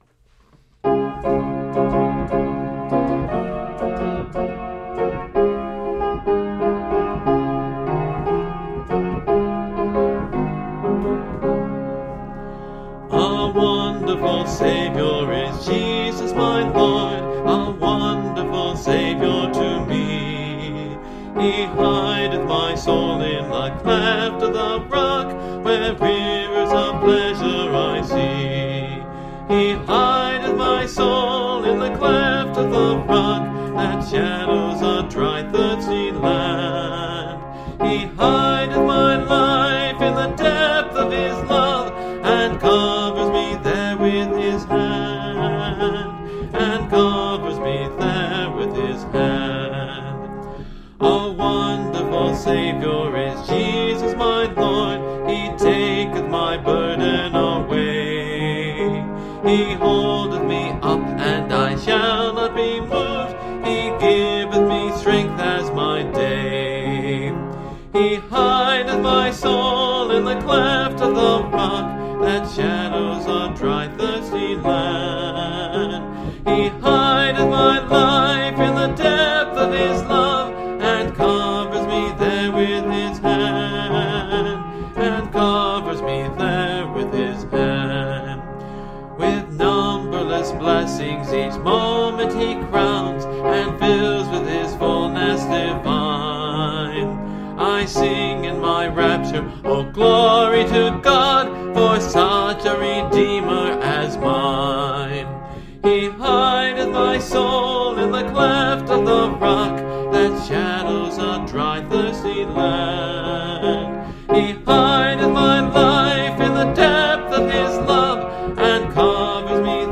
(Part of a series singing through the hymnbook I grew up with: Great Hymns of the Faith)